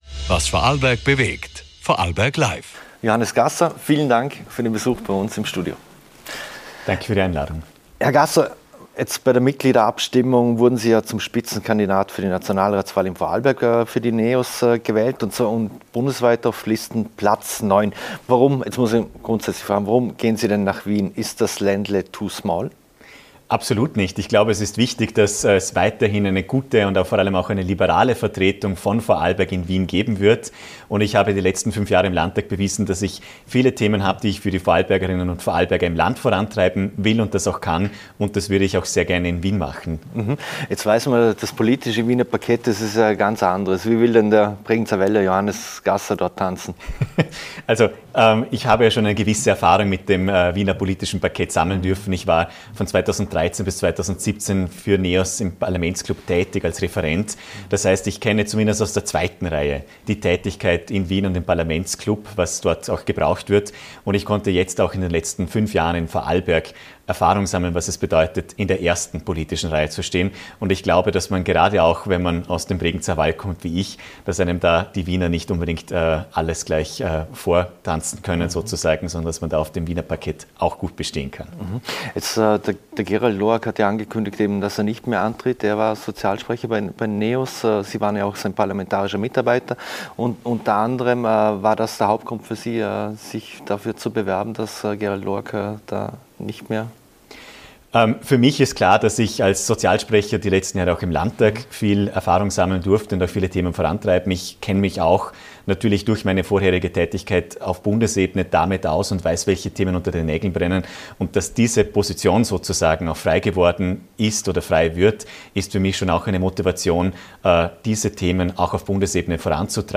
Beschreibung vor 1 Jahr Diesen Freitag zu Gast im Studio ist der Vorarlberger Spitzenkandidat der NEOS für die kommende Nationalratswahl, Johannes Gasser.